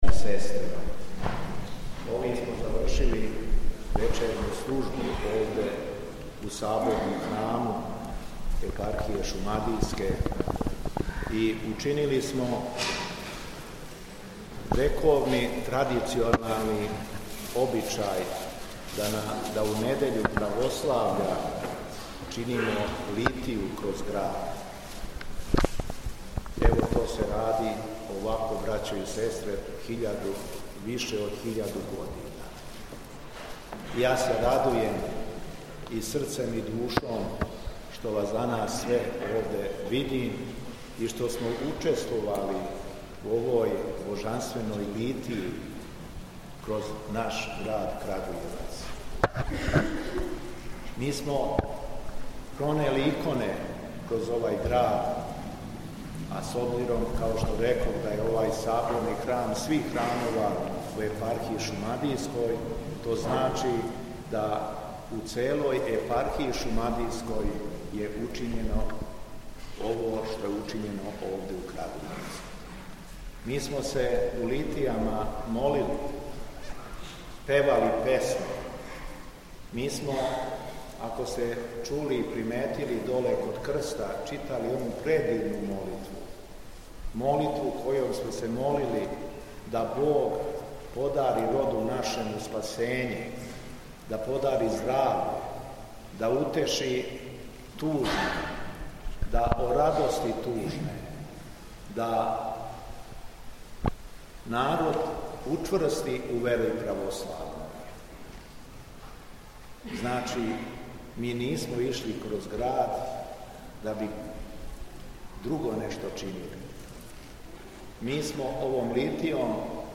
Беседа Његовог Високопреосвештенства Митрополита шумадијског г. Јована
По многољетствију које су ђакони Саборног храма произнели на крају службе, Митрополит шумадијски се свештенству, монаштву и верном народу обратио беседом: